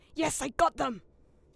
Leo theyre hit 2_1.wav